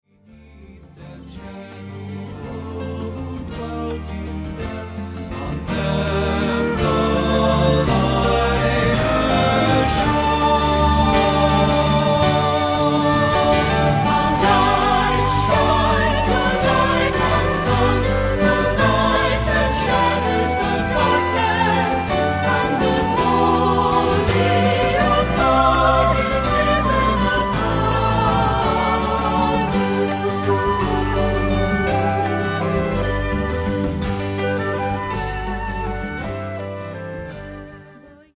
Accompaniment:      Keyboard, C Instrument I;C Instrument II
Music Category:      Choral
C instrument part is optional.